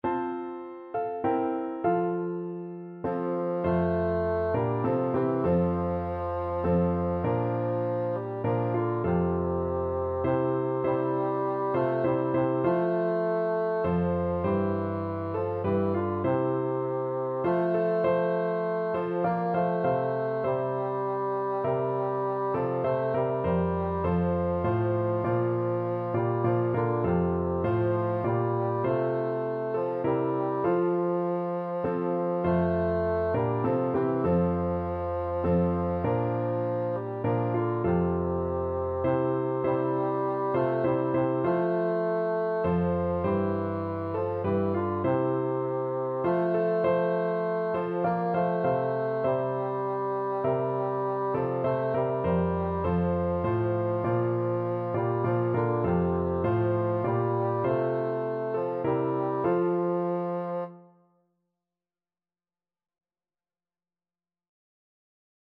Christian
3/4 (View more 3/4 Music)